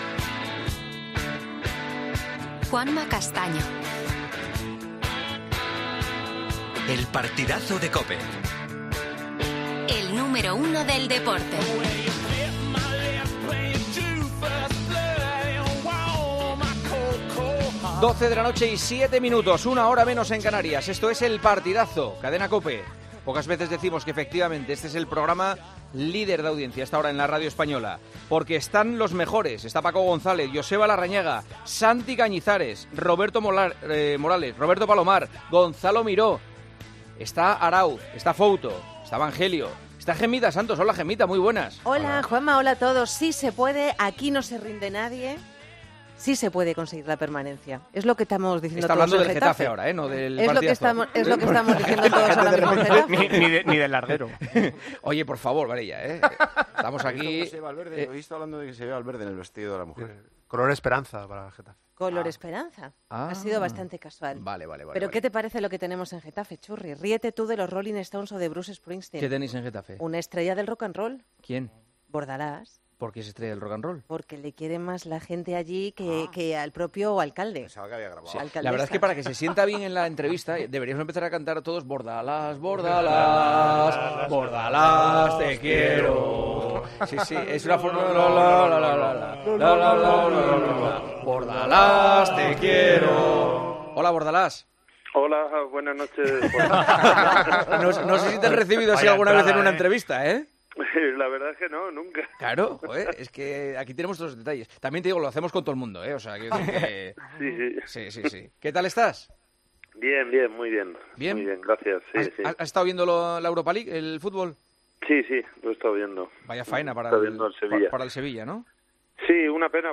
AUDIO: Juanma Castaño entrevistó al entrenador del Getafe a dos días de enfrentarse al Real Madrid con la obligación de ganar para salir del descenso.